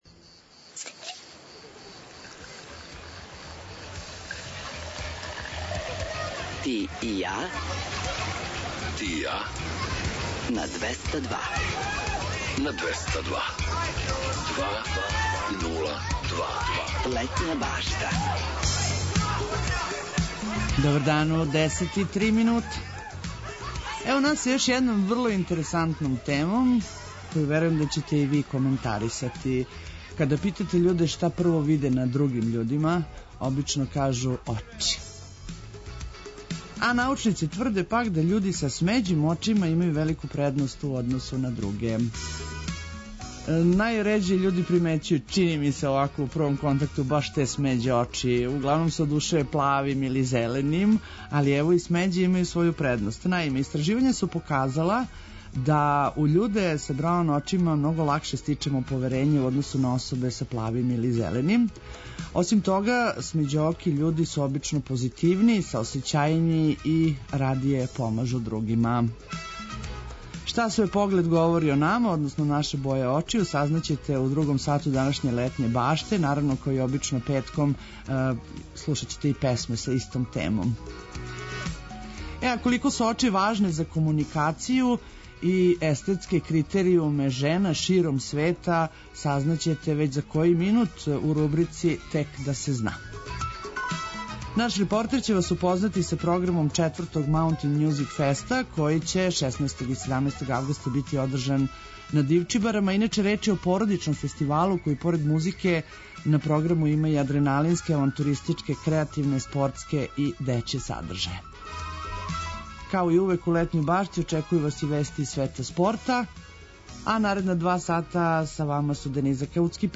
Шта све поглед говори о нама, сазнаћете у другом сату емисије, уз песме са истом тематиком. Колико су очи важне за комуникацију и естетске критеријуме жена широм света, чућете у рубрици „Тек да се зна".